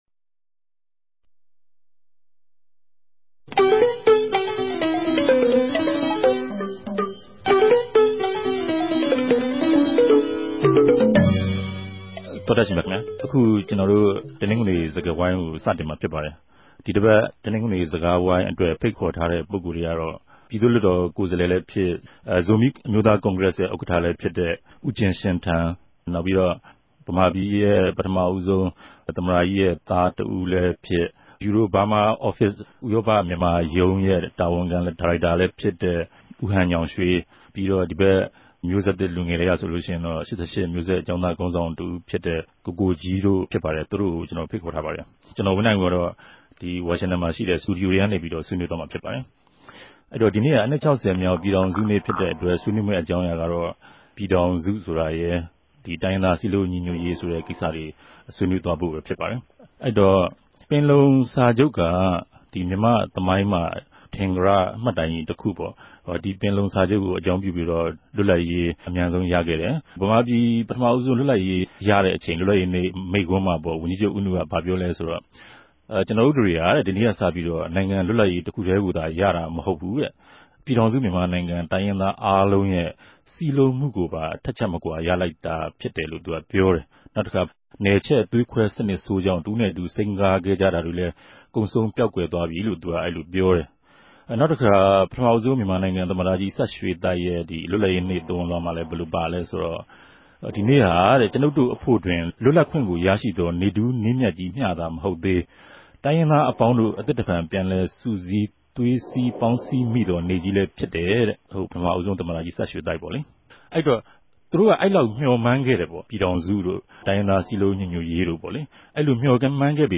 တယ်လီဖုန်းနဲႛ ဆက်သြယ် ဆြေးေိံြးထားပၝတယ်၊၊
တနဂဿေိံြ ဆြေးေိံြးပြဲစကားဝိုင်း